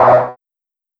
Error3.wav